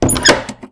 doorClose_3.ogg